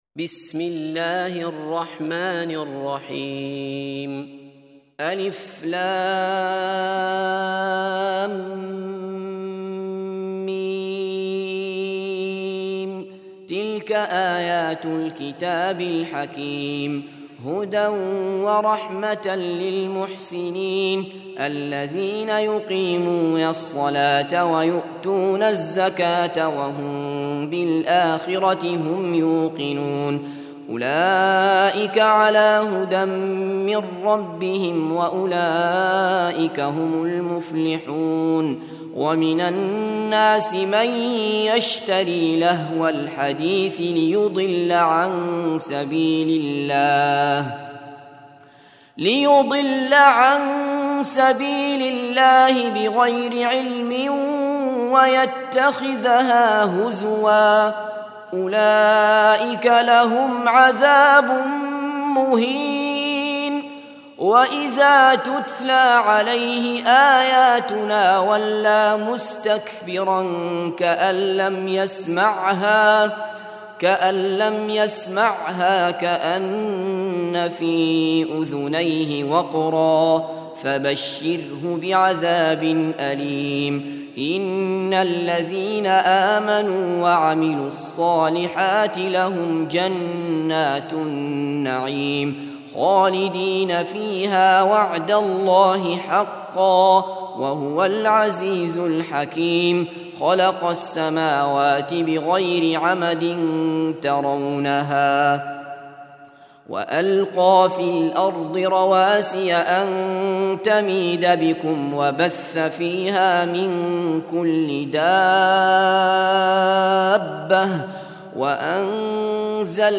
31. Surah Luqm�n سورة لقمان Audio Quran Tarteel Recitation
Surah Repeating تكرار السورة Download Surah حمّل السورة Reciting Murattalah Audio for 31.